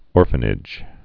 (ôrfə-nĭj)